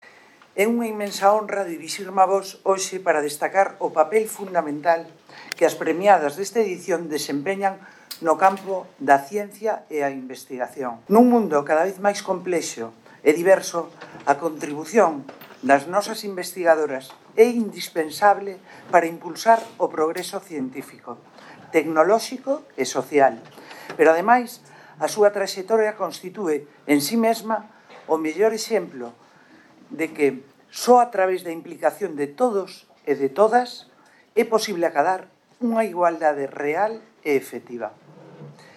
La alcaldesa de Lugo, Paula Alvarellos, hizo entrega este viernes, 8M, Día Internacional de la Mujer Trabajadora, de los galardones Entre Mulleres, premio con el que el Ayuntamiento de Lugo quiere visibilizar las aportaciones de las mujeres lucenses en todos los campos de nuestra sociedad en pro de la igualdad.